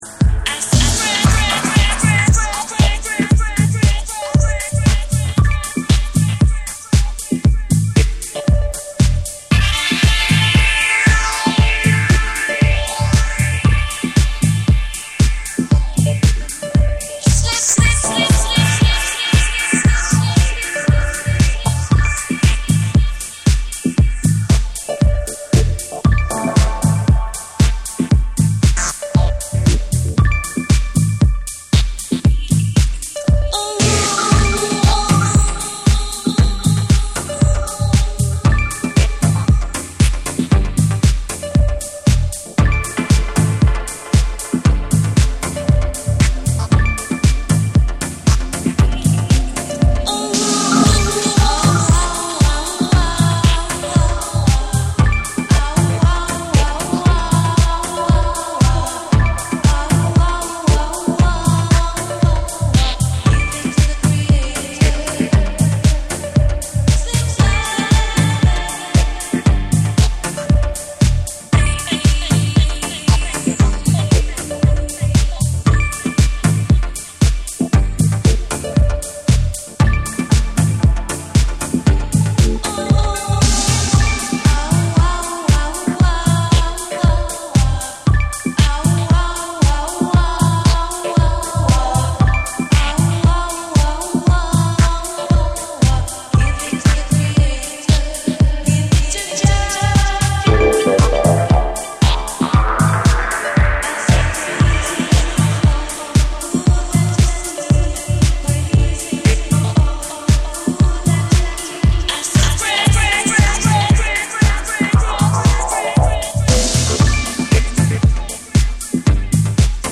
format : 12inch
JAPANESE / TECHNO & HOUSE / REGGAE & DUB